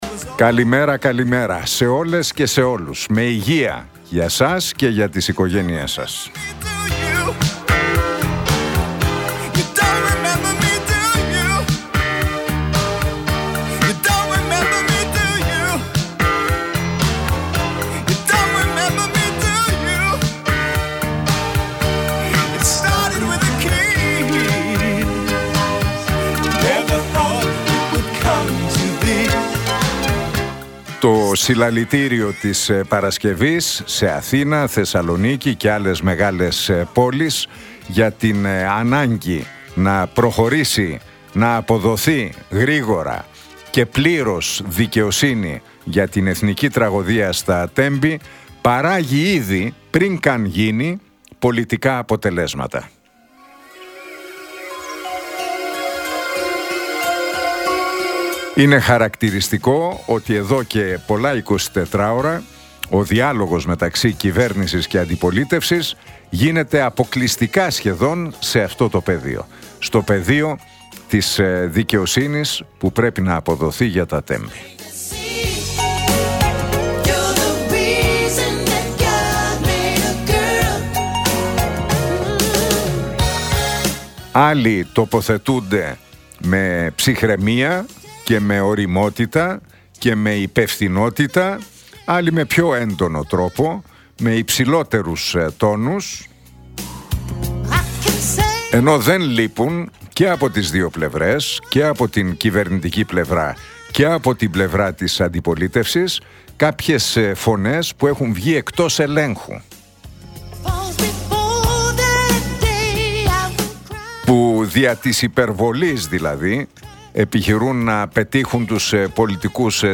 Ακούστε το σχόλιο του Νίκου Χατζηνικολάου στον ραδιοφωνικό σταθμό RealFm 97,8, την Τρίτη 25 Φεβρουαρίου 2025.